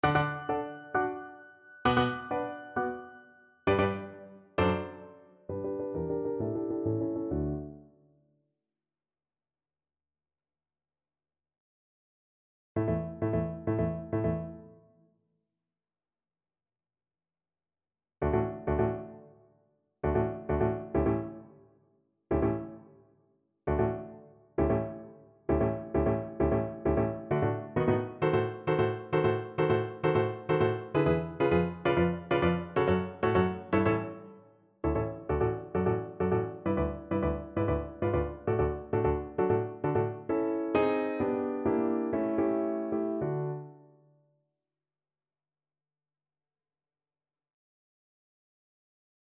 Play (or use space bar on your keyboard) Pause Music Playalong - Piano Accompaniment Playalong Band Accompaniment not yet available transpose reset tempo print settings full screen
D major (Sounding Pitch) (View more D major Music for Cello )
Allegro agitato e appassionato assai = 132 (View more music marked Allegro)
Classical (View more Classical Cello Music)